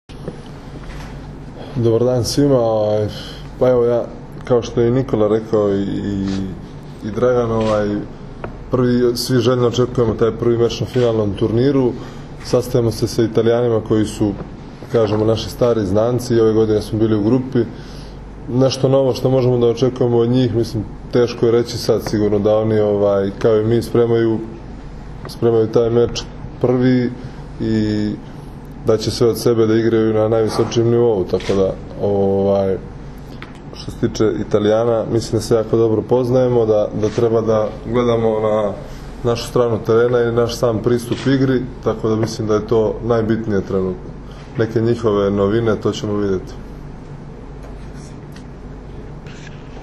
IZJAVA NEMANJE PETRIĆA